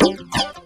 junky guitar.wav